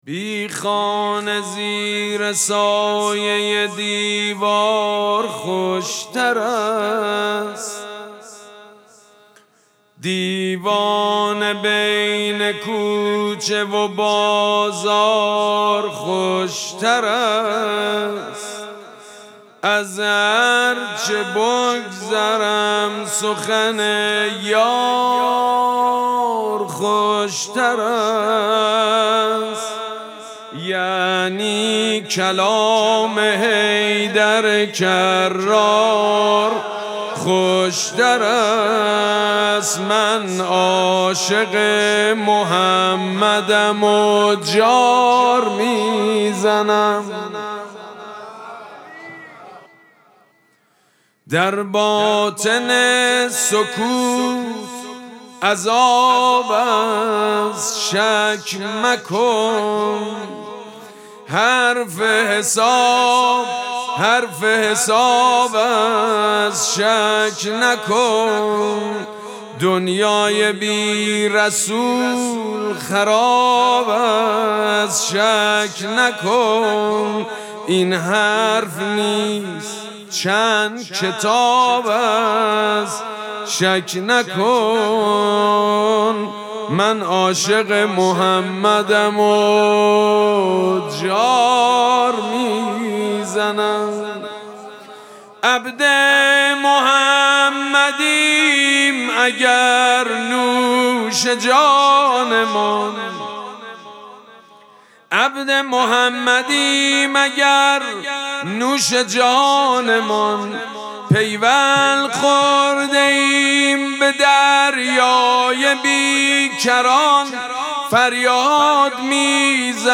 مراسم مناجات شب دوم ماه مبارک رمضان ‌‌‌‌یکشنبه ۱۲ اسفند ماه ۱۴۰۳ | ۱ رمضان ۱۴۴۶ ‌‌‌‌‌‌‌‌‌‌‌‌‌هیئت ریحانه الحسین سلام الله علیها
سبک اثــر شعر خوانی مداح حاج سید مجید بنی فاطمه